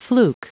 Prononciation du mot fluke en anglais (fichier audio)
Prononciation du mot : fluke